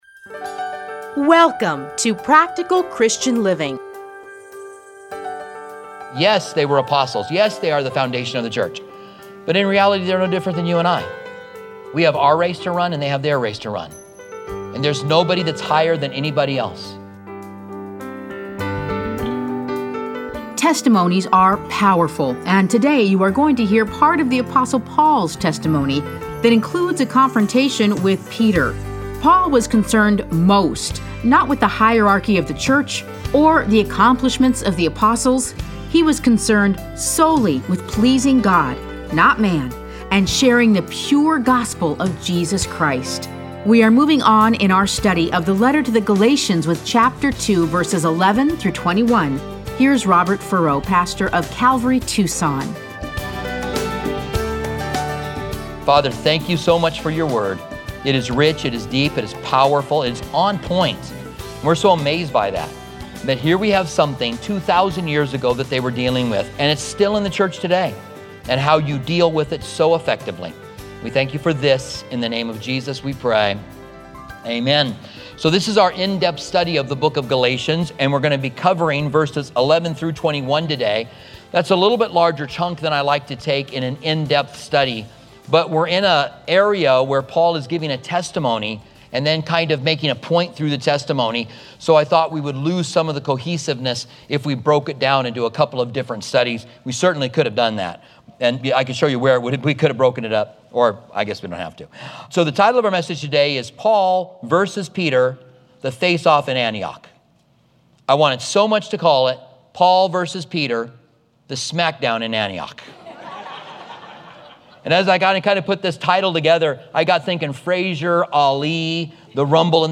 Listen to a teaching from Galatians 2:11-21.